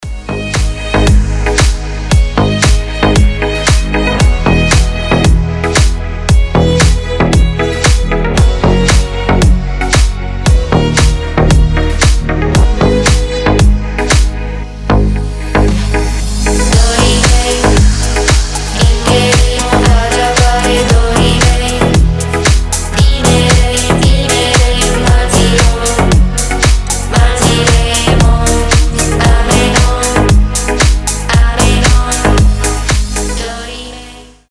красивые
deep house
скрипка
ремиксы